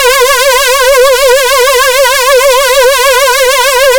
Crazy Lead.wav